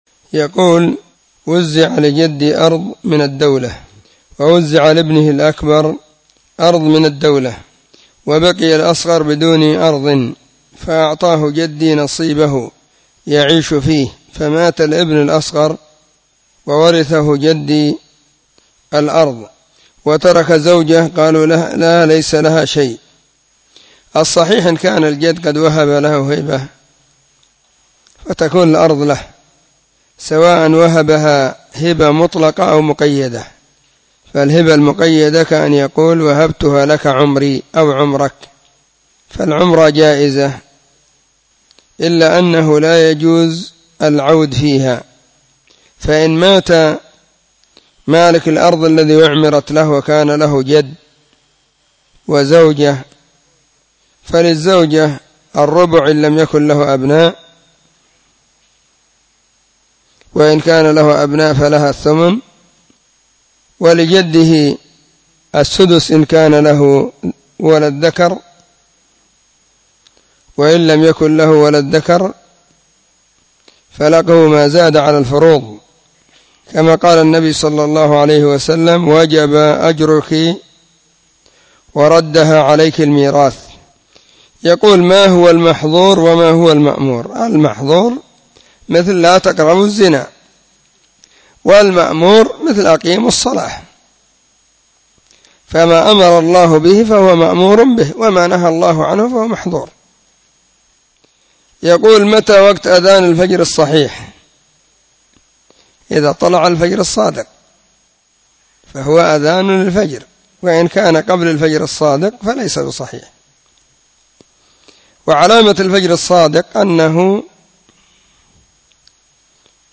🔹 سلسلة الفتاوى الصوتية 🔸